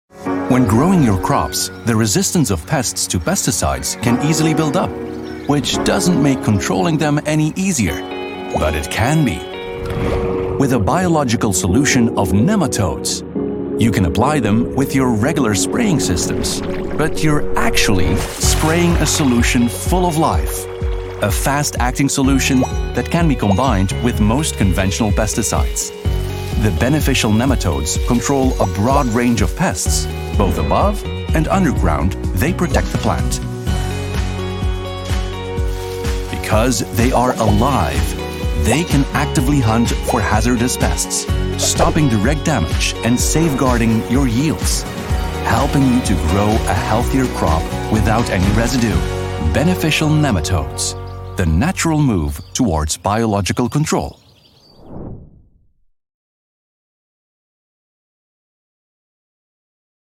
Anglais (International)
Cool, Polyvalente, Fiable, Profonde, Naturelle
Vidéo explicative